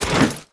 戴满装备的人在行走-YS070515.wav
通用动作/01人物/01移动状态/戴满装备的人在行走-YS070515.wav